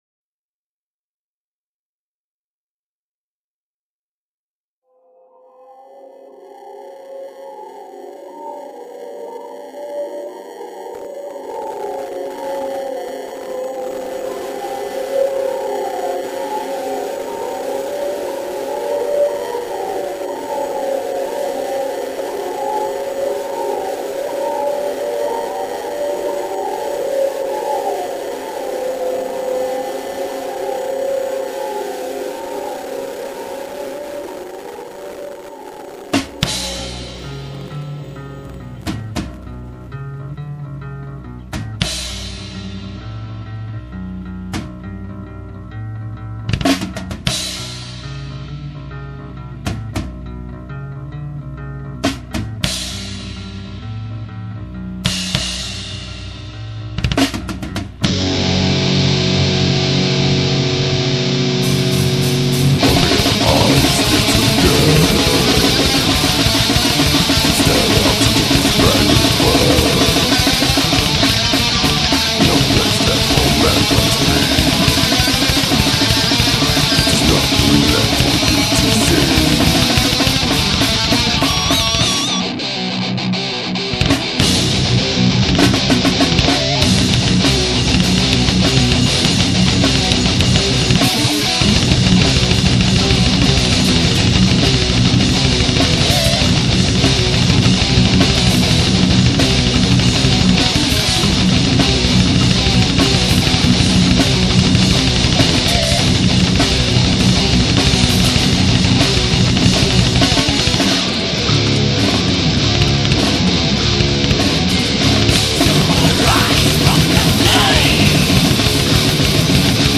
this is some heavy stuff, man.
but still, it rocks pretty damn hard!